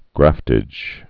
(grăftĭj)